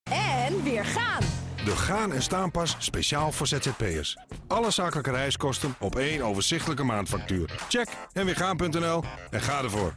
Hieronder enkele radiocommercials: